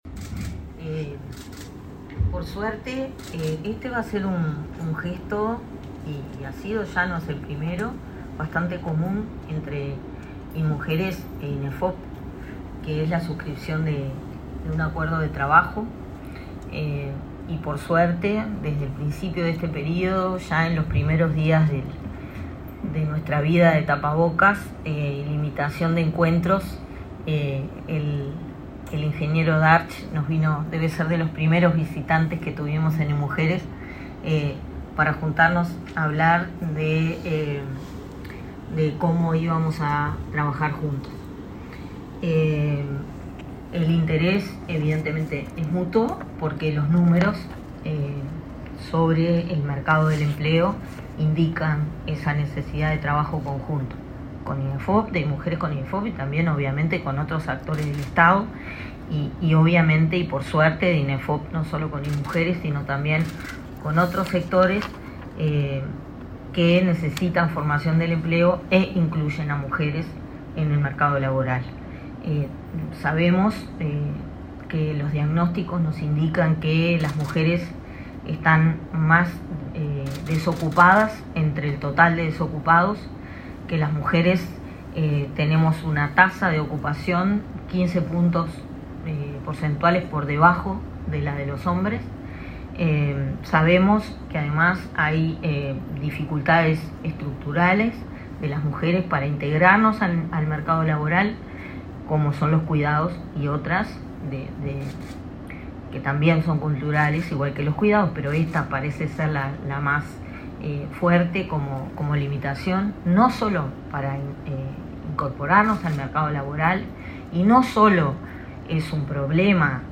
Declaraciones de Darscht y Bottero tras firma de convenio entre Mides e Inefop